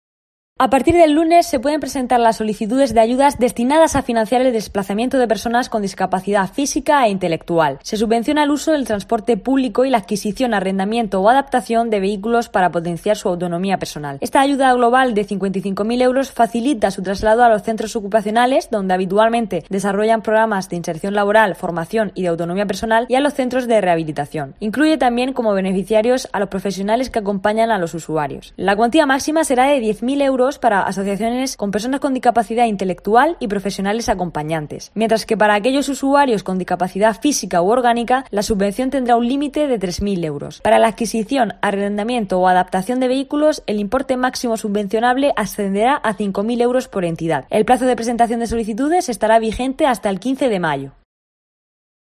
Marina Munuera, directora general de Movilidad y Litoral